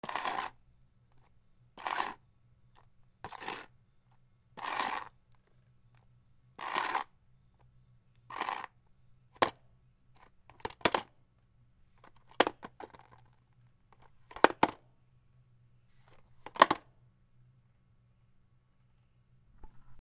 プラスチックケースをずらす 軽く落とす
/ J｜フォーリー(布ずれ・動作) / J-10 ｜転ぶ　落ちる